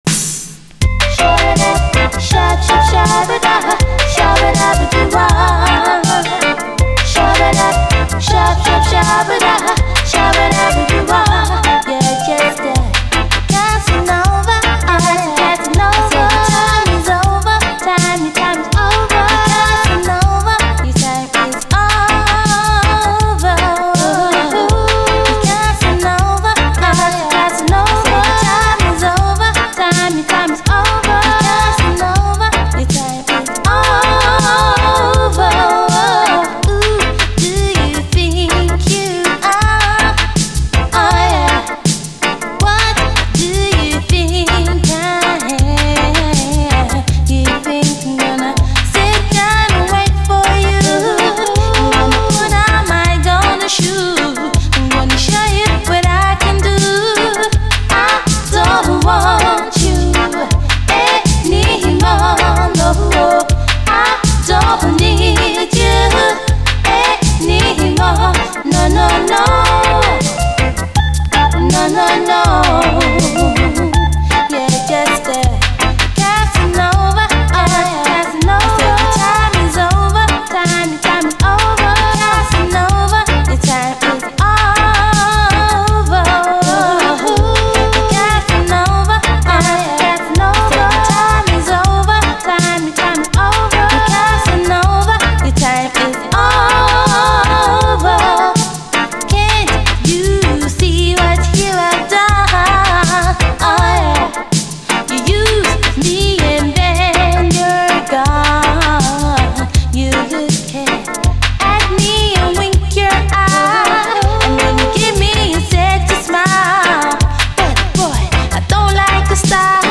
REGGAE
♪シャバダバ・コーラスからキャッチー＆キュートな最高UKラヴァーズ！